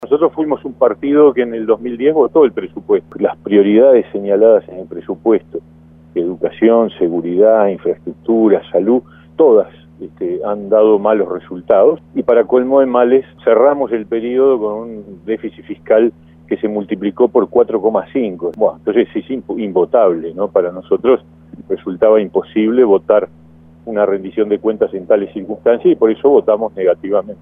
El Senador del Partido Independiente, Pablo Mieres, dijo radio El Espectador que el gobierno no logró ninguna de las metas previstas en 2010, incluso prometió un déficit fiscal del 0,8 por ciento y terminó siendo de 3,5%.